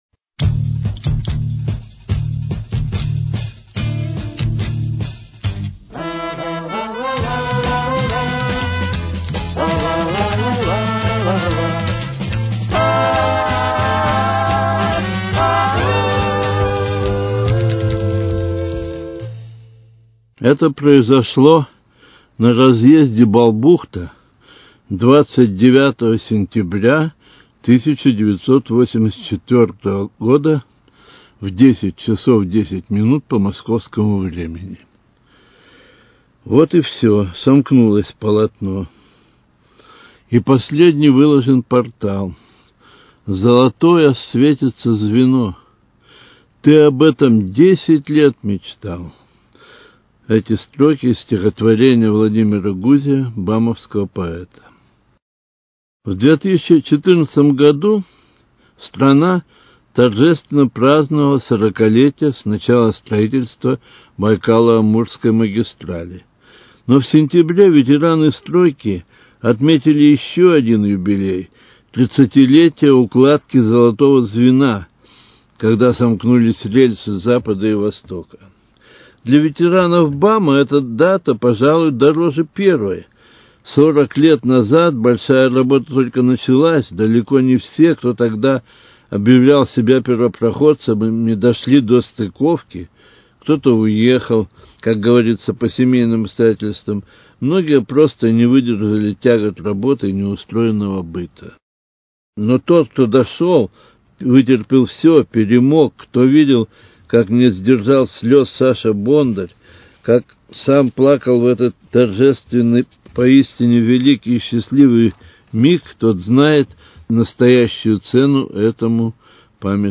Цикл радио-очерков